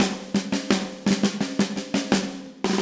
Index of /musicradar/80s-heat-samples/85bpm
AM_MiliSnareC_85-01.wav